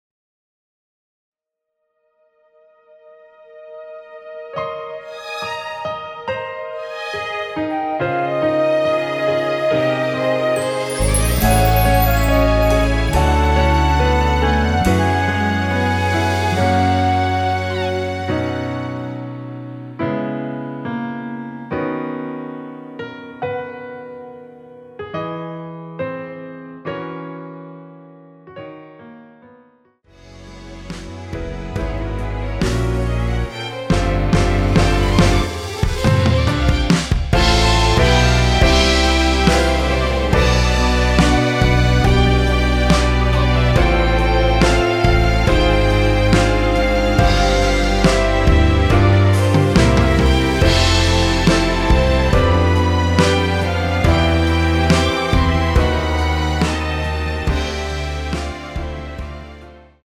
원키에서(+1)올린 MR입니다.
앞부분30초, 뒷부분30초씩 편집해서 올려 드리고 있습니다.
곡명 옆 (-1)은 반음 내림, (+1)은 반음 올림 입니다.